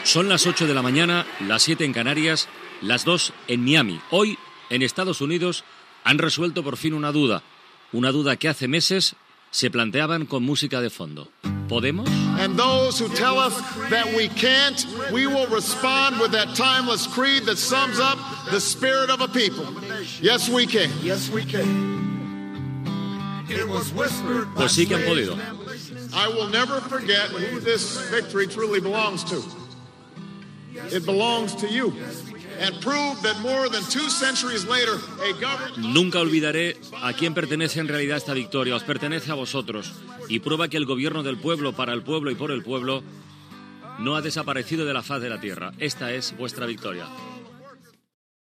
Hora, informació que el senador demòcrata Barack Obama serà president dels Estats Units, amb fragments del seu discurs de la nit electoral, pronunciat al Grant Park de Chicago
Info-entreteniment